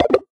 ulti_button_cancel_01.ogg